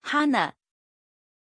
Pronunciation of Hana
pronunciation-hana-zh.mp3